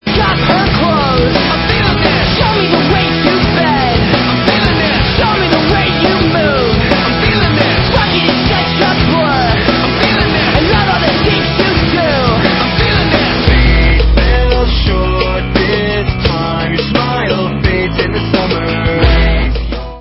Rock/Punk